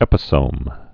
(ĕpĭ-sōm)